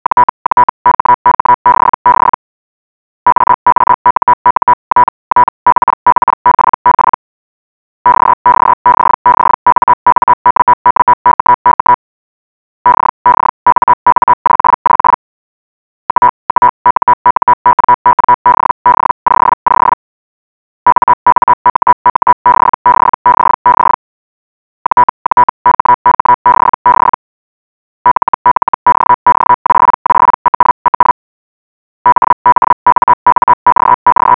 All files are 8 kHz mono, ~34 seconds, generated by HellGen.
Clean baseline at 980 Hz — start here537 KB
hell_qbf_980hz_clean.wav